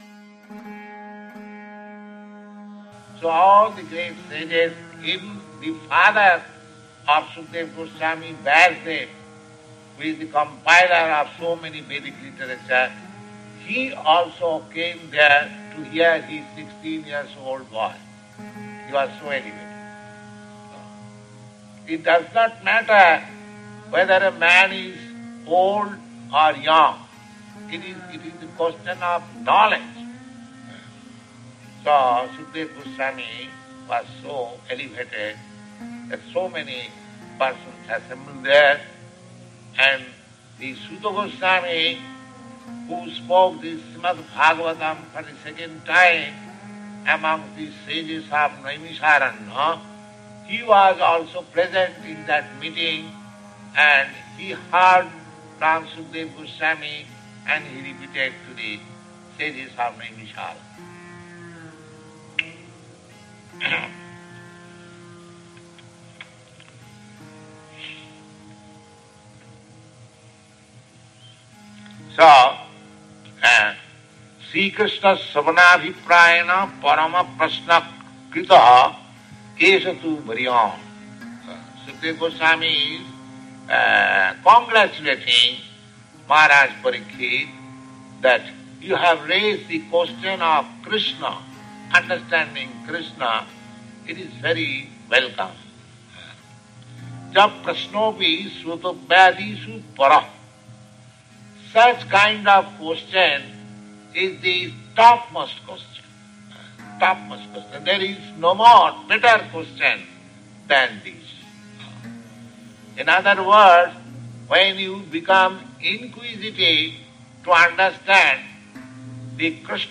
(680620 - Lecture SB 01.04.25 - Montreal)